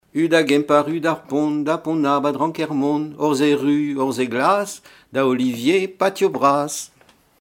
formulette enfantine : sauteuse
Pièce musicale inédite